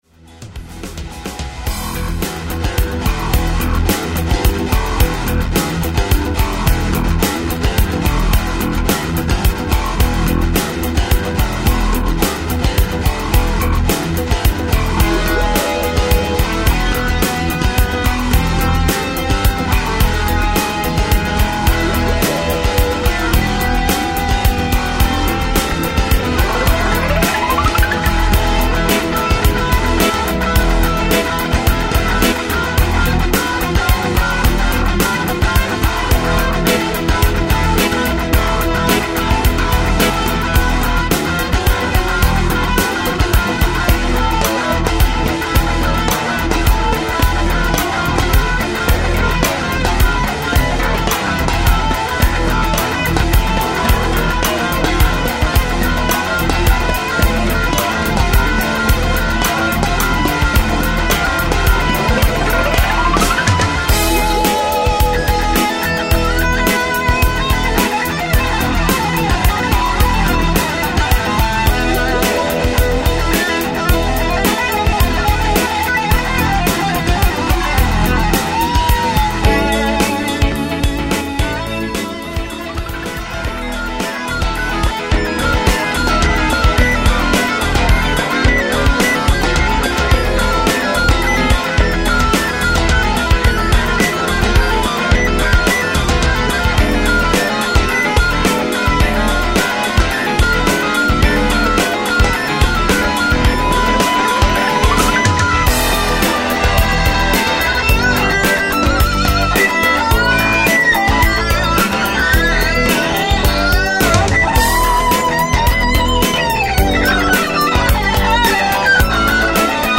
Там вроде как 3 партии по голосам.
Но динамика и тембр мп3шки плохо ложится даже на 16 битную Wav-ку.